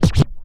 scratch11.wav